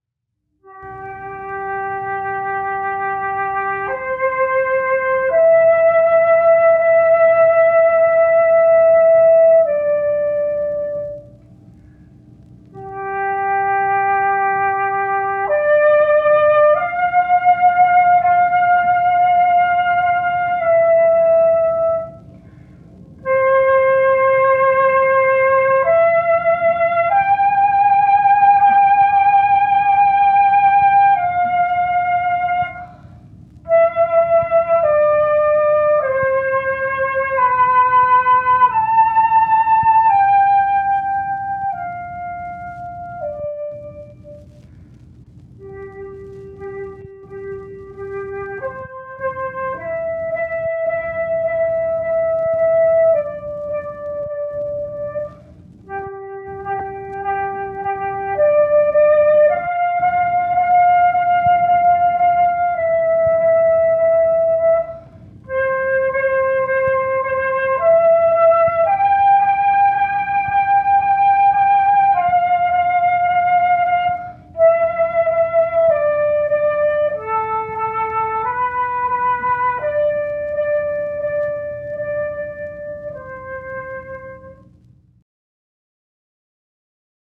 Playing his study No. 1
Moyse playing Melodious study number 1 complete with bell tones.